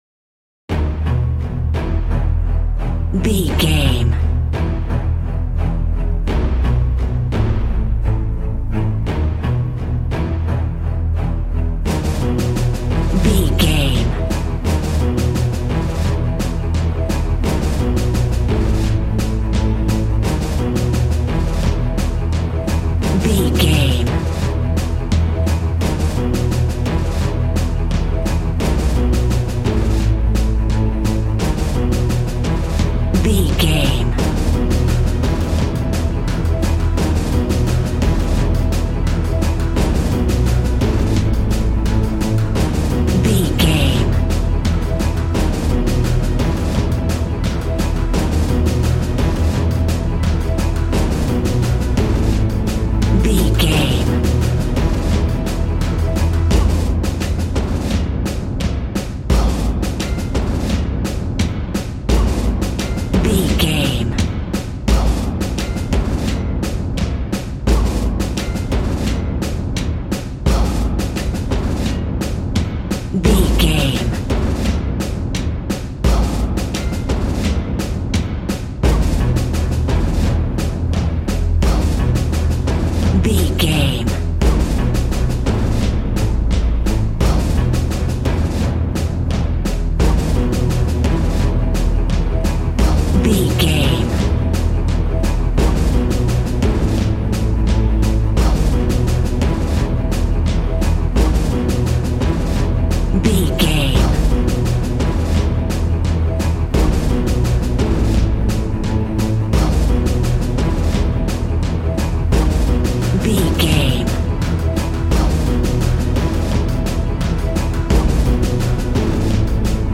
Epic / Action
Fast paced
In-crescendo
Uplifting
Ionian/Major
brass
percussion
synthesiser